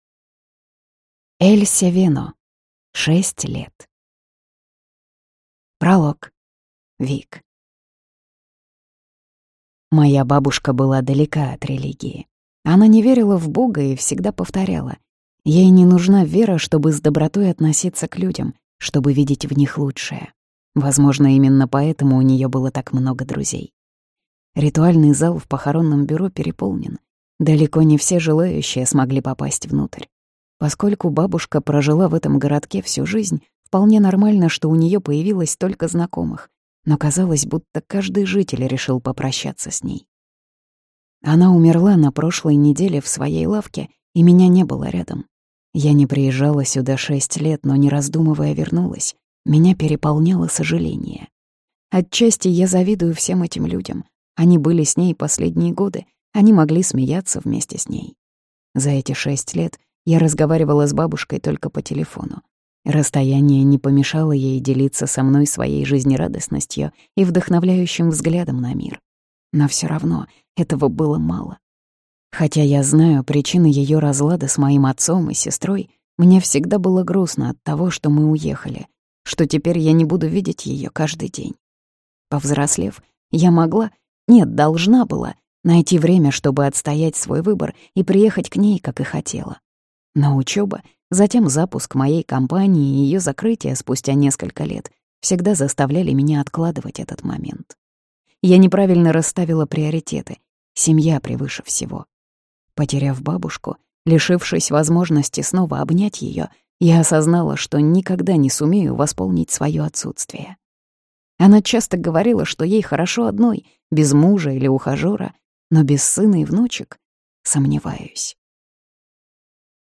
Аудиокнига Шесть лет | Библиотека аудиокниг